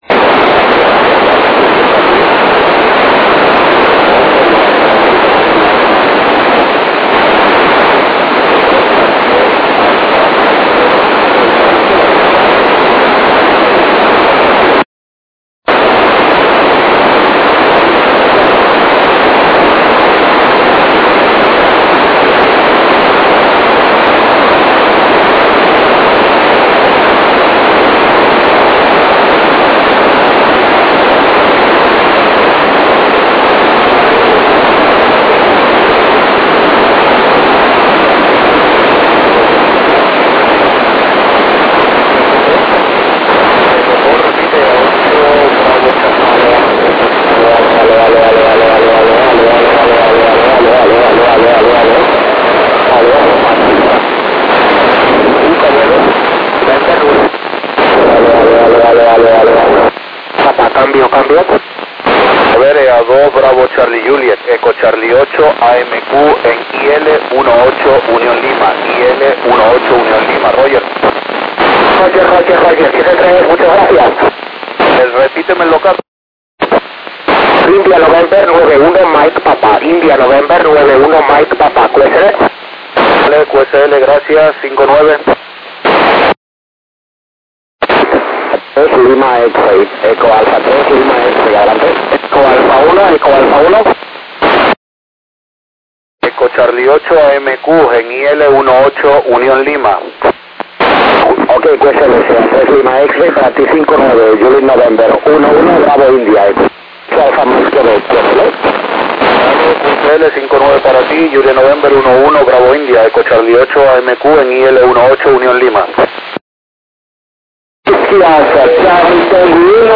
Spanish Stations.
Recibido en un pase elevado con IC-706MKIIG y J-Pole bibanda casera desde Cordoba.